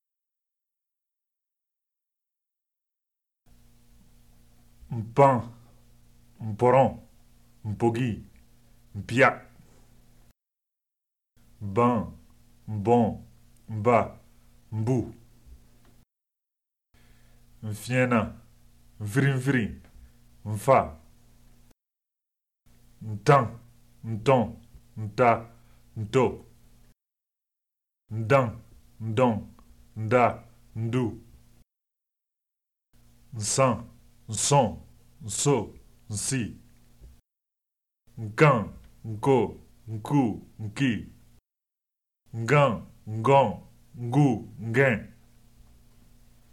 CONSONNES PRÉ-NASALISÉES
groupes exemples pour s’entraîner
01a-06-consonnes-prenasalisees.mp3